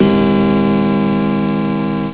grandfathersclock.wav